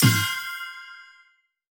drum-spinnerbonus.wav